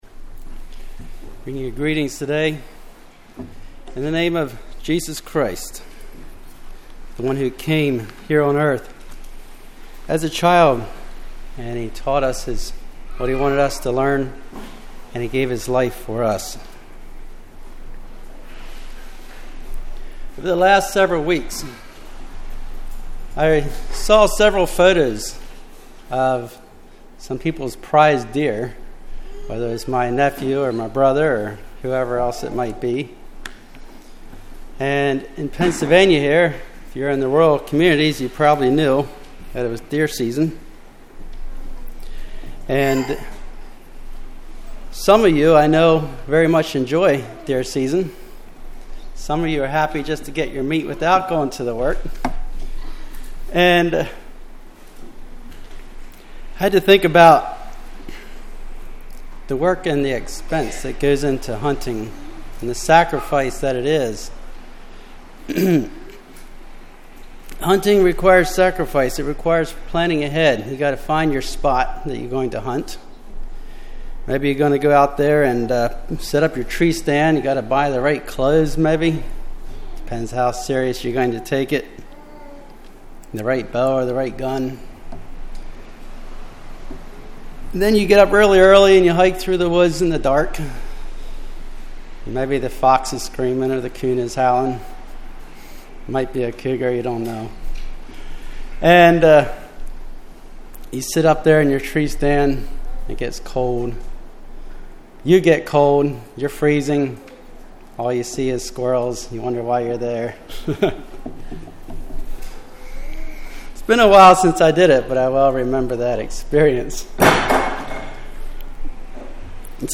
Congregation: Hidden River